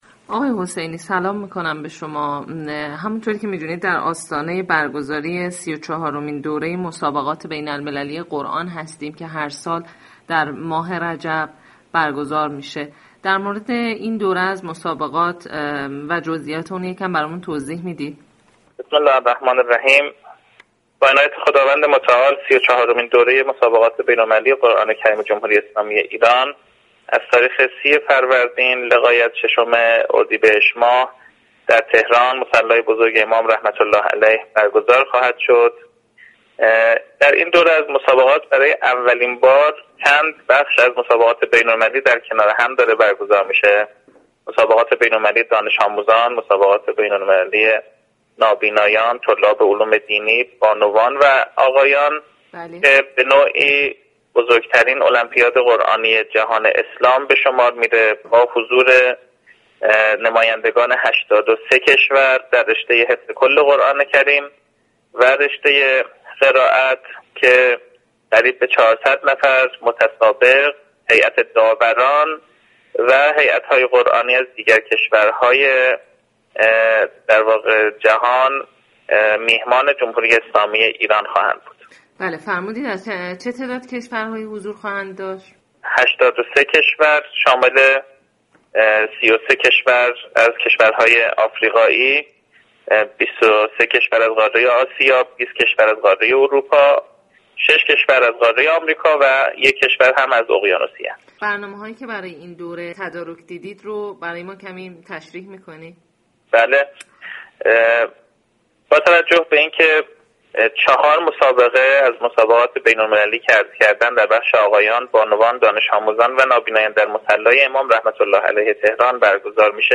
در گفتگو با سایت رادیو فرهنگ